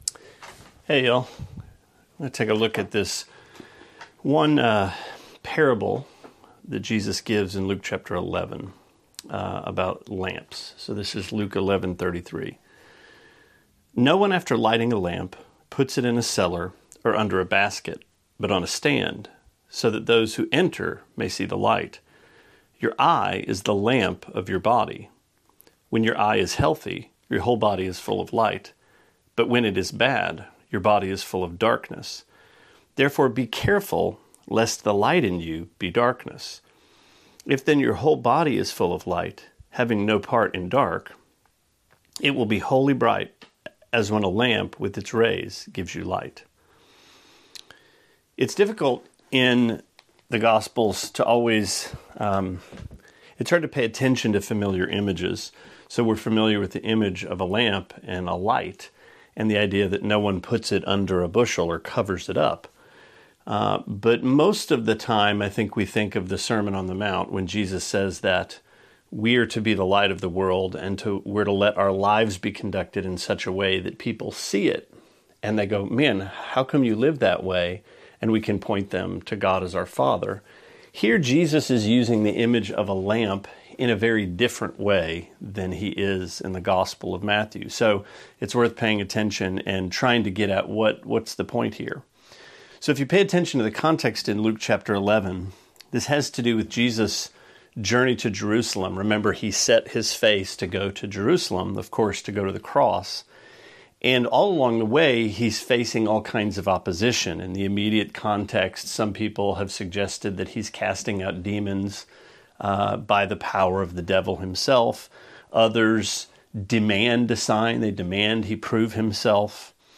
Sermonette 10/11: Luke 11:33-36: How’s Your Lamp